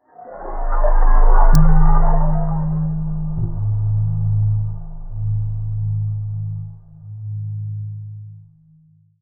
UI_SFX_Pack_61_38.wav